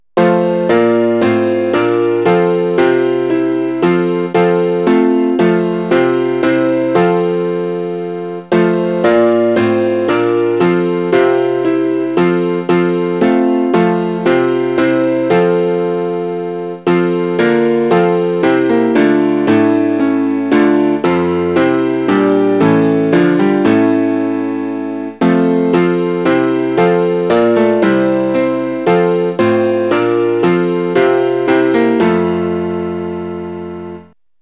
Midi file is modified to piano only.